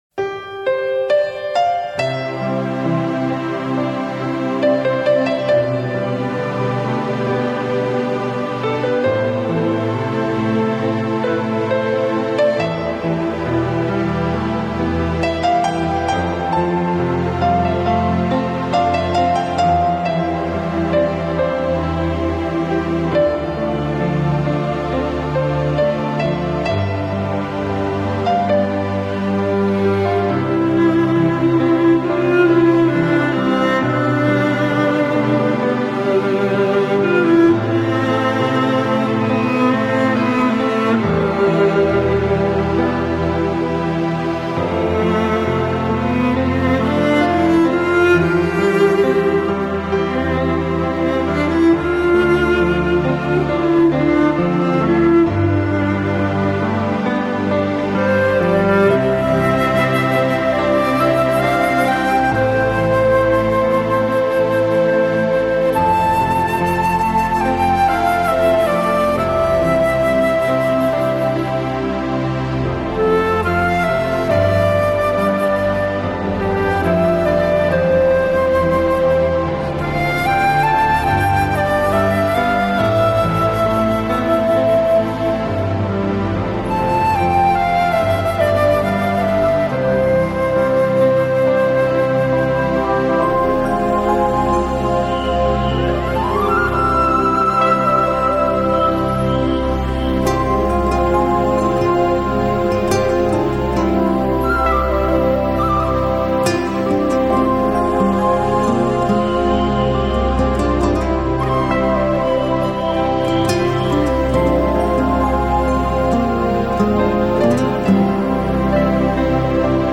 旋律优美流畅